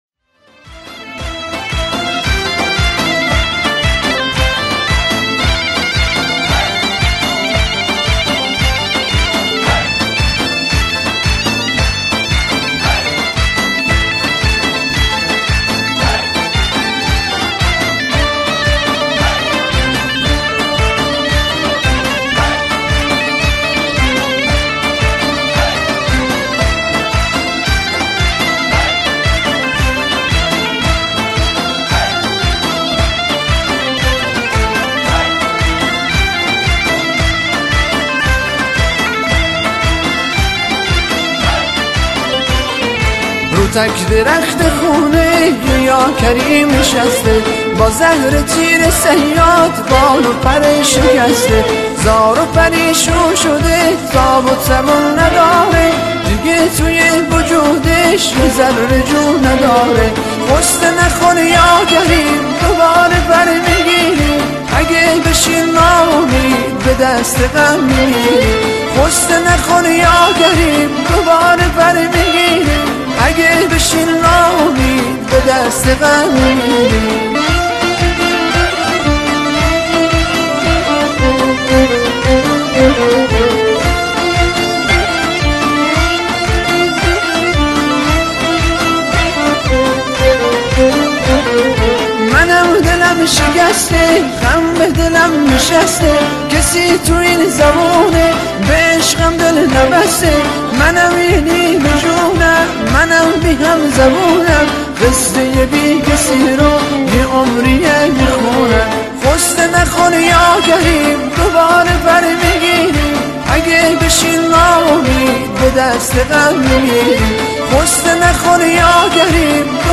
کوردی